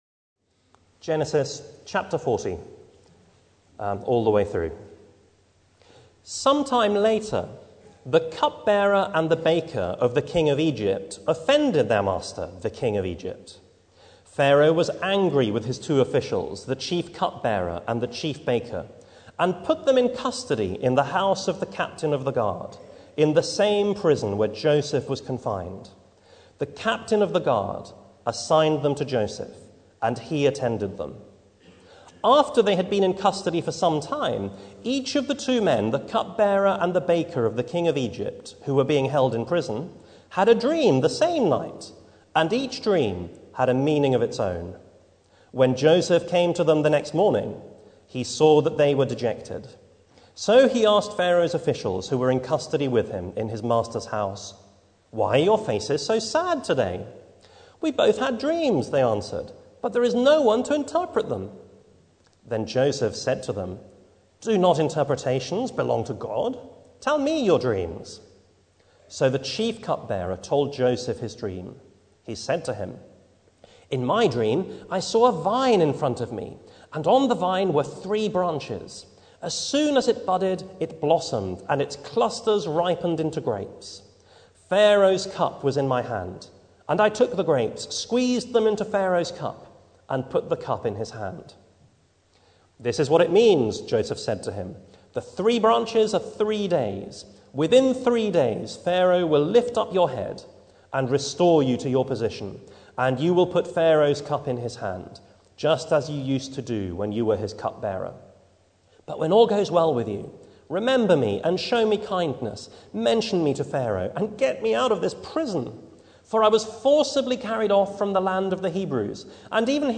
Passage: Genesis 40 Service Type: Sunday Morning